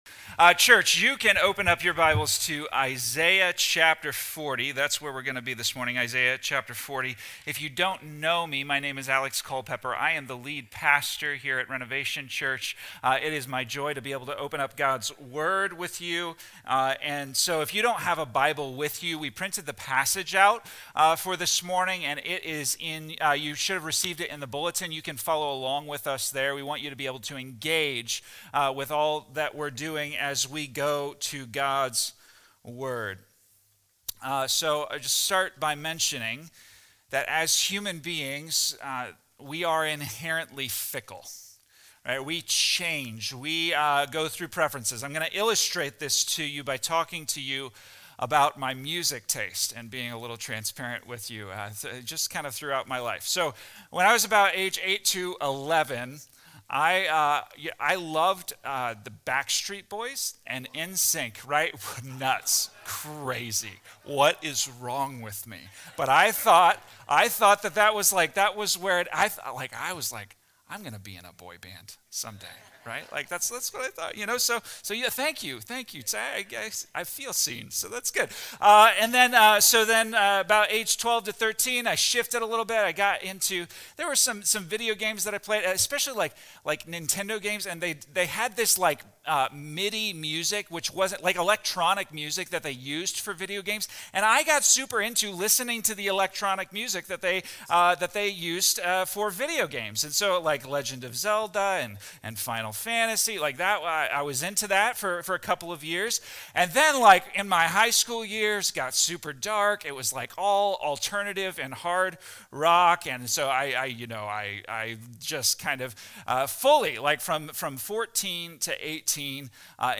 This sermon dives deep into the beauty of God’s unchanging nature, even when life feels overwhelming. It reminds us how easy it is to project our human doubts and frustrations onto God, thinking He’s distant or has forgotten us.